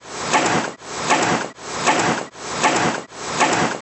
kanye-west-hitting-his-head.wav